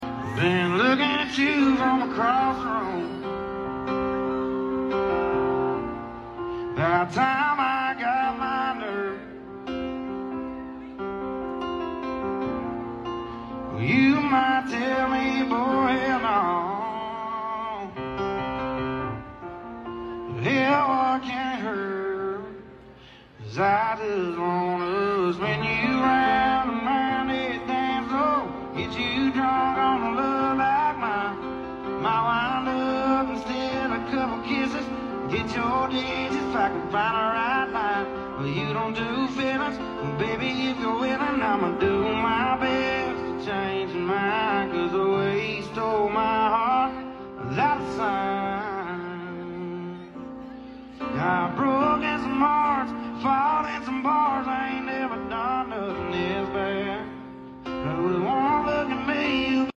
country music
bass, drums
guitar